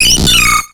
Cri de Marill dans Pokémon X et Y.